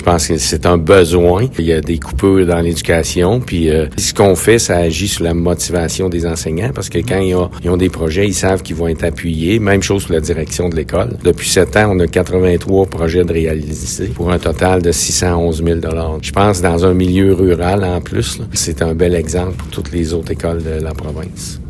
En conférence de presse lundi avant-midi, il a été annoncé que la Fondation a réussi également à remettre près de 5 000 $ en bourses aux élèves.